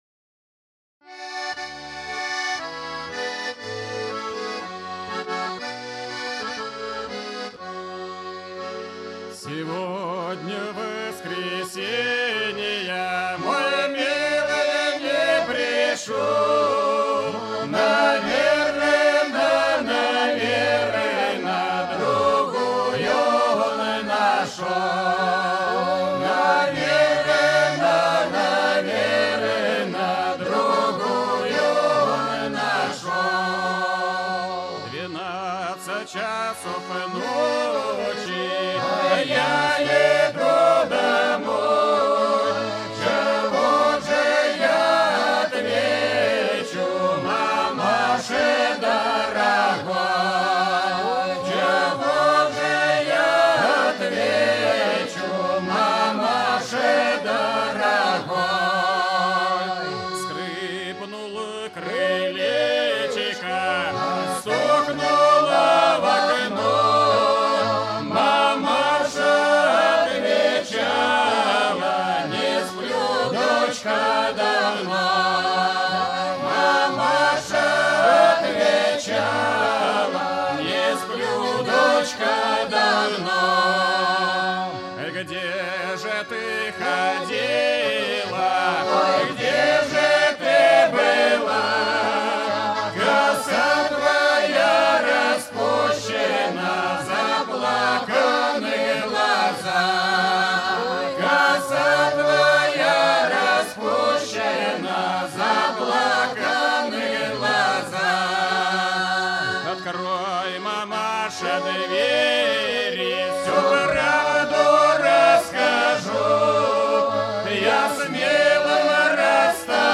• Качество: Хорошее
• Жанр: Детские песни
Ансамбль казачьей песни